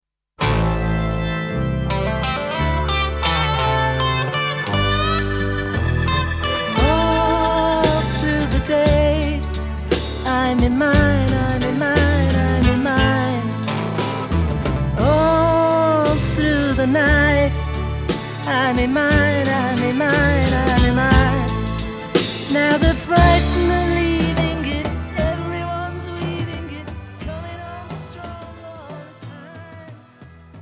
zpěv, kytara
basová kytara, klávesy
bicí